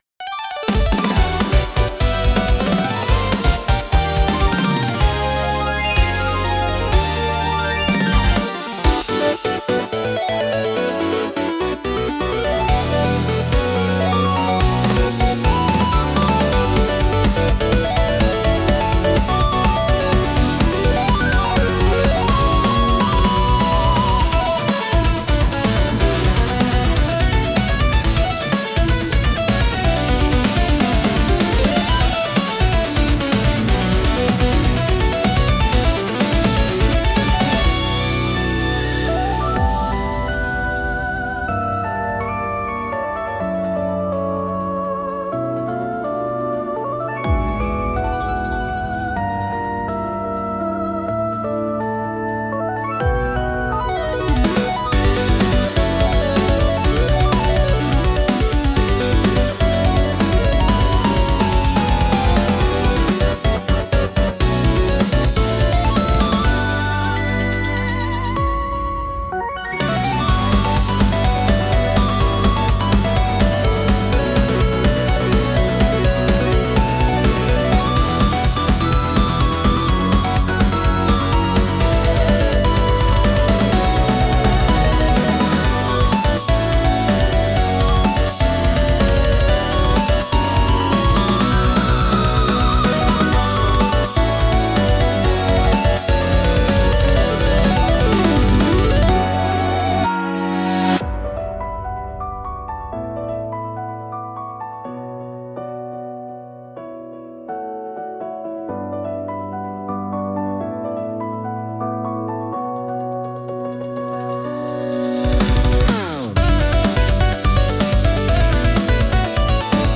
速いビートとエネルギッシュなサウンドがスピード感や緊張感を強調し、プレイヤーの集中力を高めます。
アップテンポでスタイリッシュな曲調が特徴のジャンルです。
テンポの速いリズムと洗練されたサウンドで、スリルと爽快感を演出し、没入感を促進します。